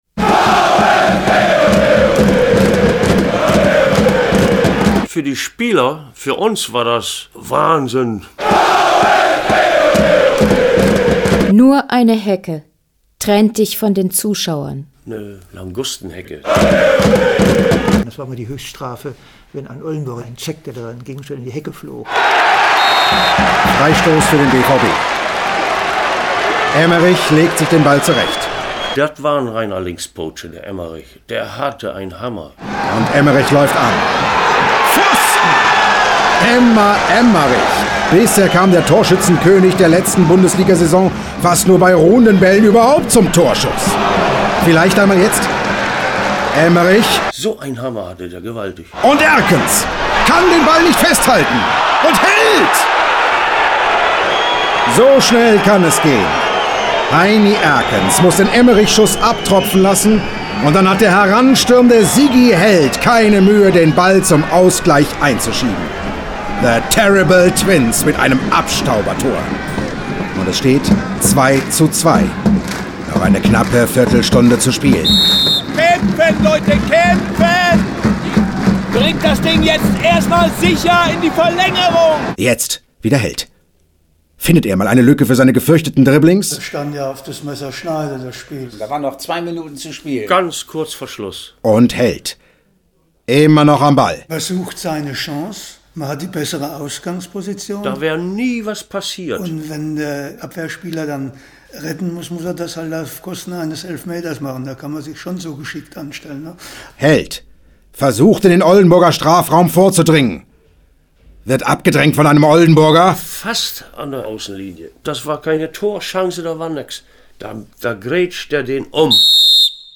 Hörspiel- und Theaterprojekt des Staatstheaters Oldenburg
Sounddesign und Schnitt
Sprecherinnen und Sprecher:
O-Ton-Stimmen: